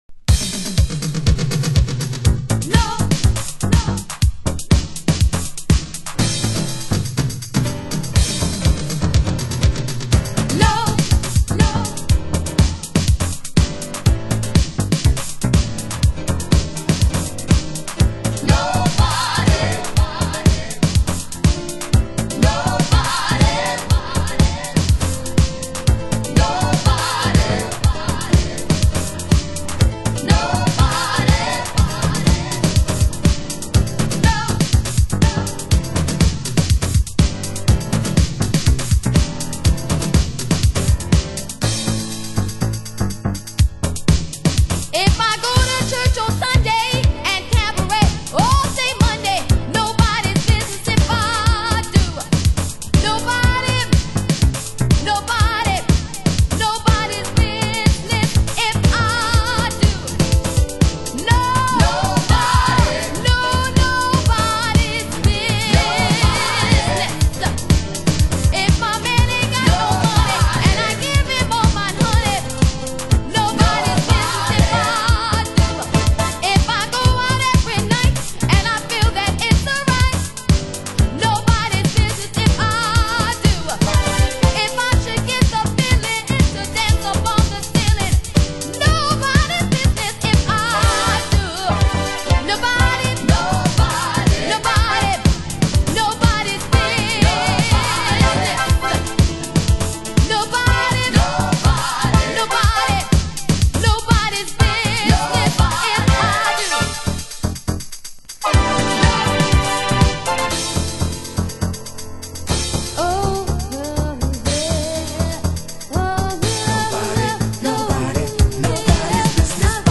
盤質：B面前半〜中盤にかけてスレ傷 有（試聴箇所になっています）/少しチリパチノイズ有